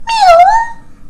MEW.mp3